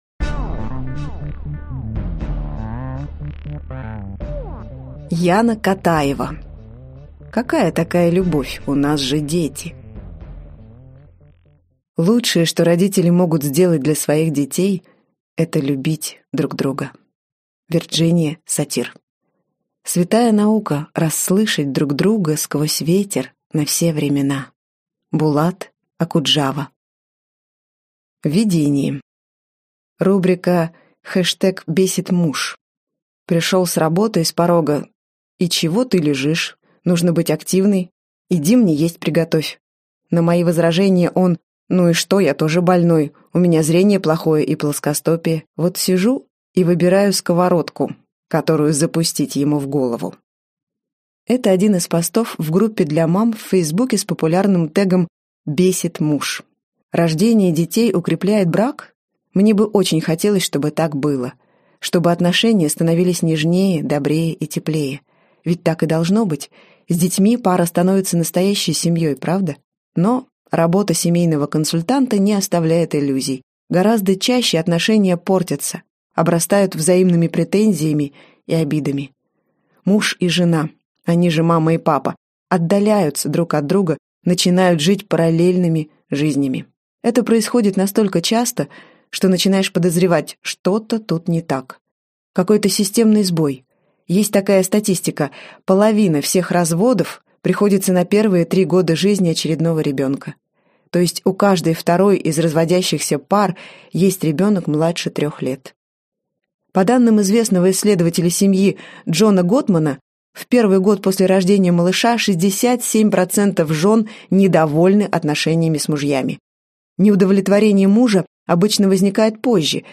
Аудиокнига Какая такая любовь, у нас же дети!